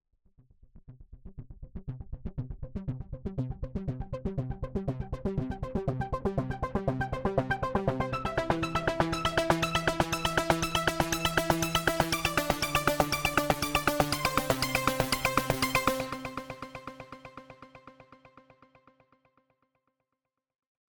Durch die röhrenbasierten Oszillatoren und vielen Variationsmöglichkeiten von Parametern lässt ein sehr warmer und griffiger Klang erzeugen.
Es sind viele spezielle Klänge dabei, die ich so oft bei Synthesizern noch nicht gehört habe.
air_vaccum_pro_testbericht_seq_1.mp3